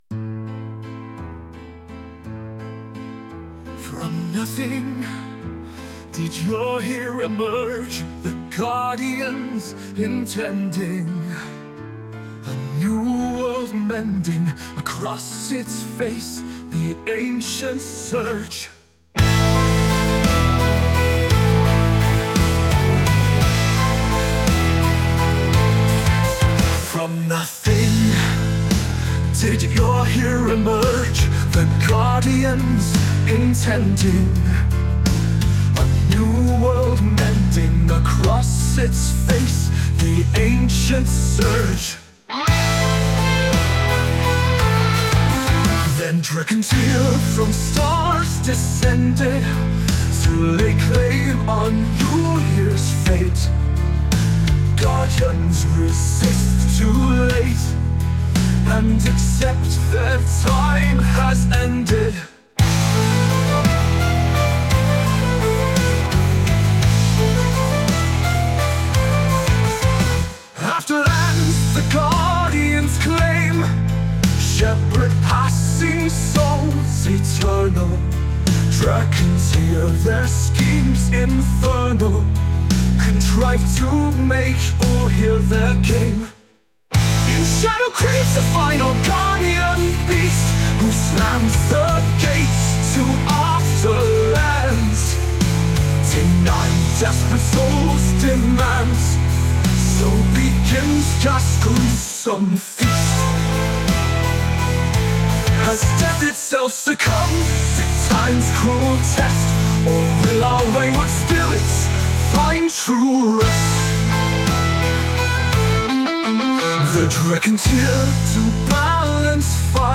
As Griff flips through the pages, a ballad printed in the back plays magically from the book.